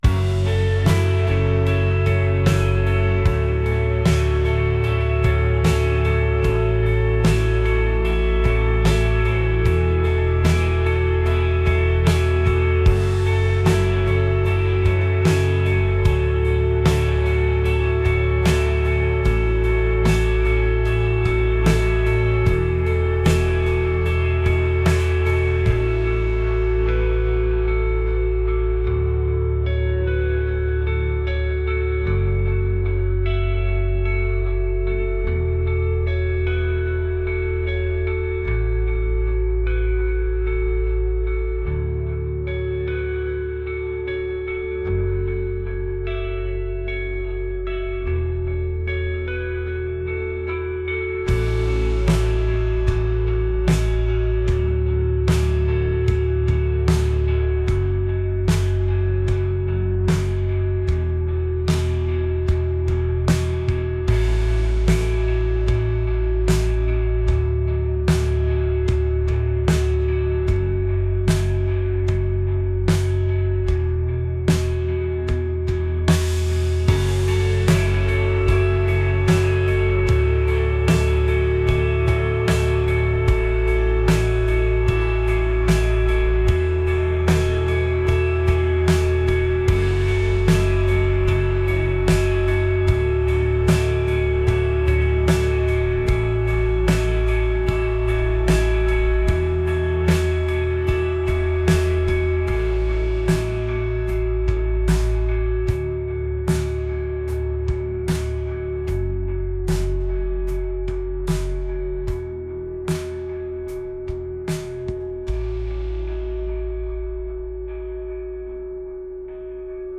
dreamy | indie | rock